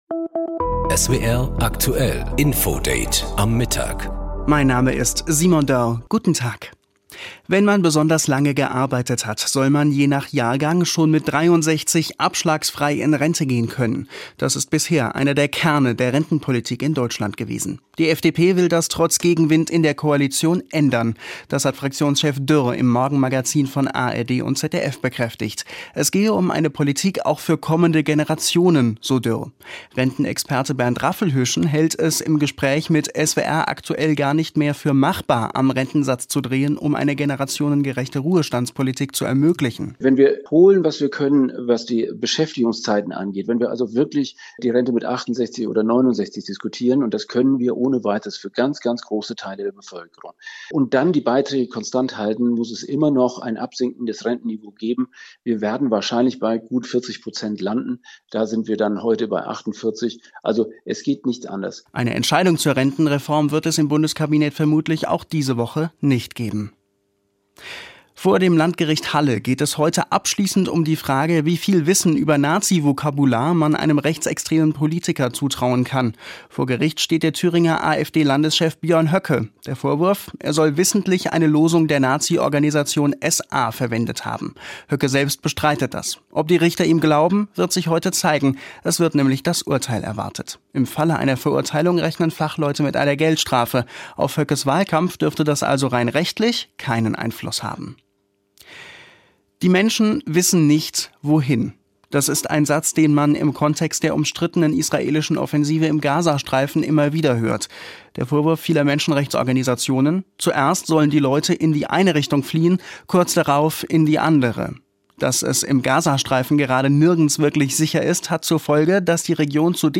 Rentenexperte Bernd Raffelhüschen hält es im Gespräch mit SWR Aktuell für gar nicht mehr machbar, am Rentensatz zu drehen, um eine generationengerechte Ruhestandspolitik zu ermöglichen.